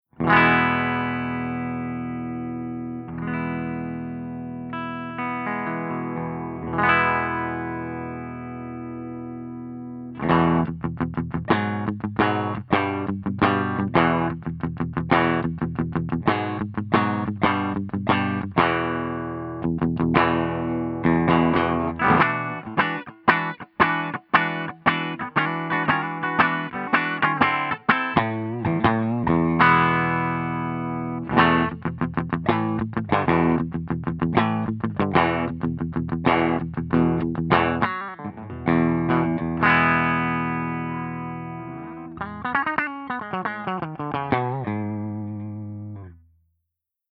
038_FENDER75_STANDARD_P90